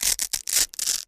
Velcro is pulled apart at various speeds. Tear, Velcro Rip, Velcro